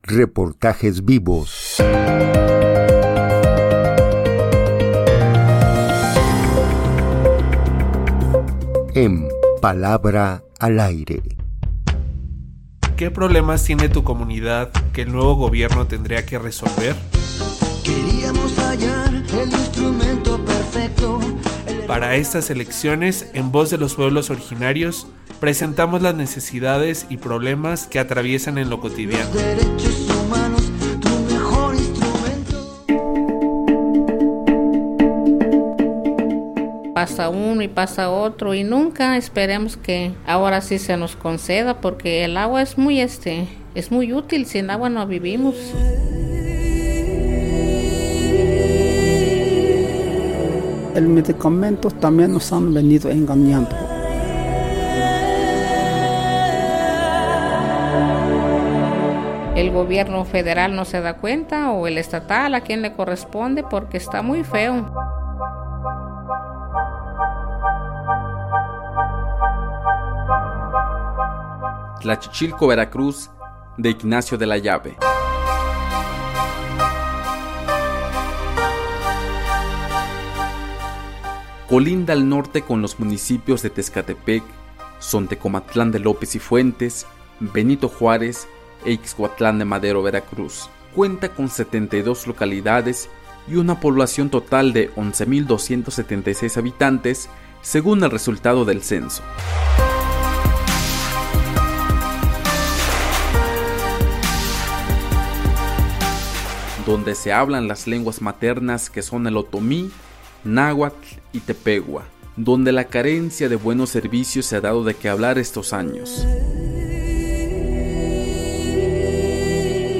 Falta de medicamentos, buenas condiciones en las carreteras, falta de agua, seguridad pública. Escuchemos la palabra de la gente en las comunidades vecinas de Tlachichilco, Veracruz.
reportaje-semanal_necesidades-en-las-comunidades-de-Tlachichilco-Veracruz.mp3